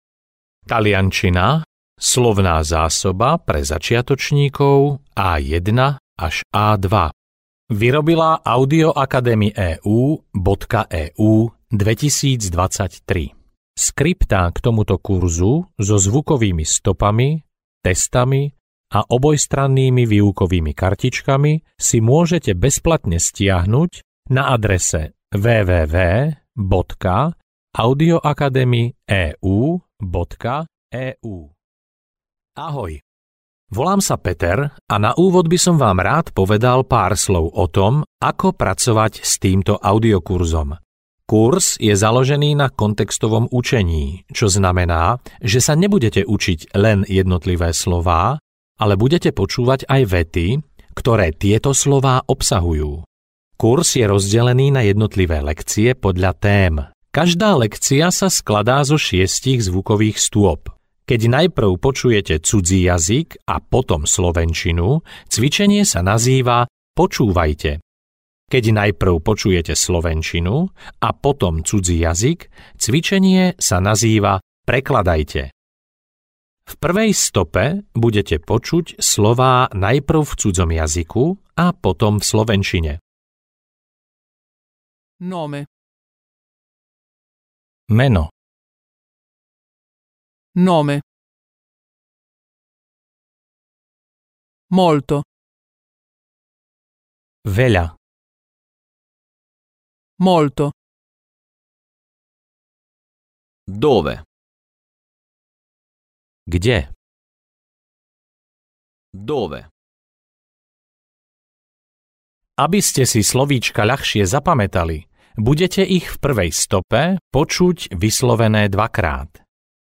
Taliančina pre začiatočníkov A1-A2 audiokniha
Ukázka z knihy